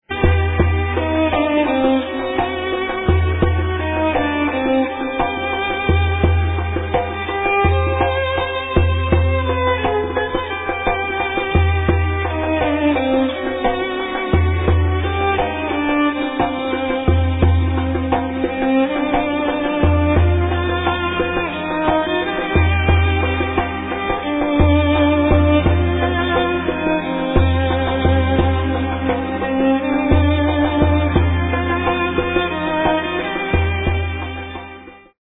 kawalaa reed-flute typical of Egyptian folk music
nayan Arab reed-flute
'uda Middle Eastern short-necked plucked lute